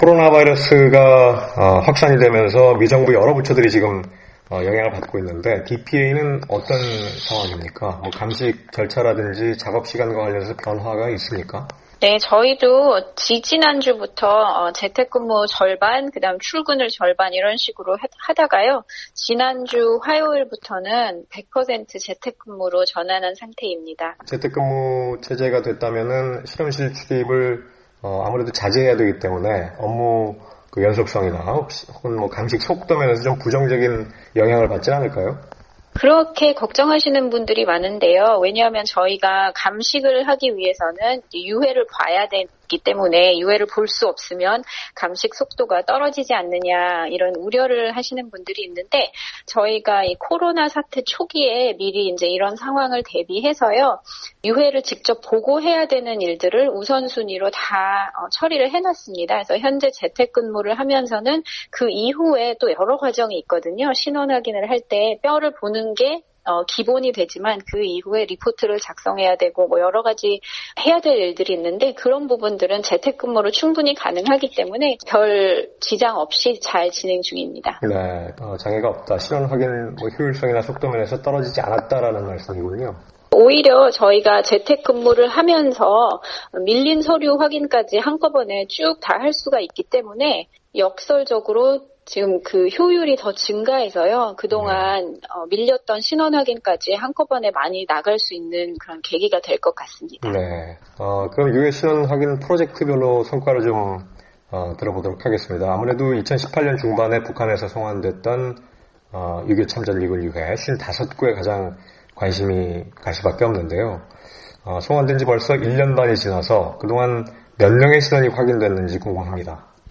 [인터뷰